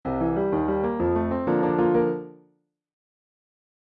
NFblroTnXNW_wingrandpiano-96338.mp3